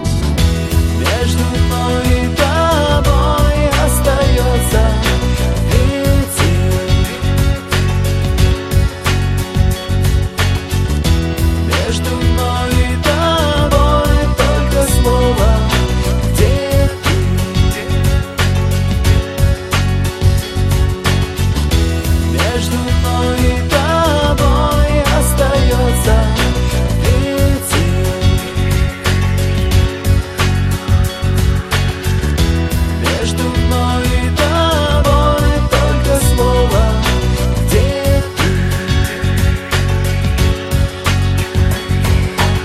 • Качество: 112, Stereo
спокойные
Спокойная композиция